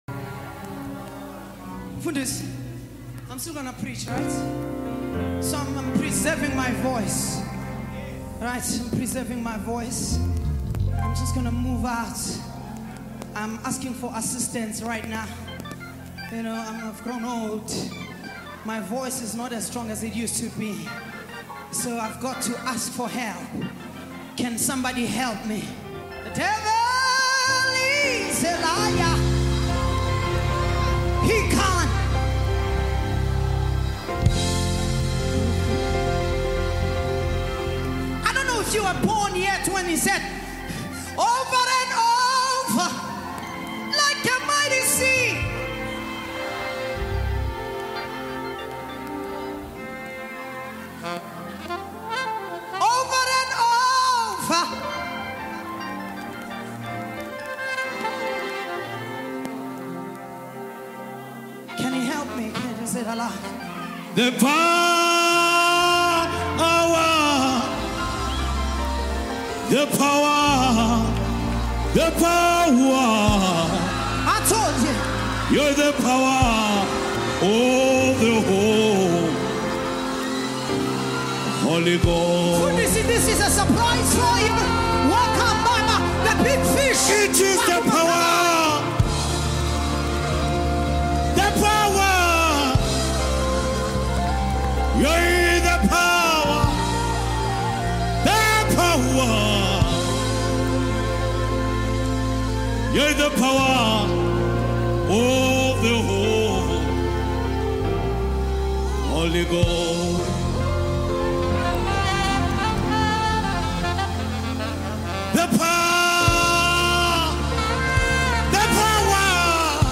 • Genre: Gospel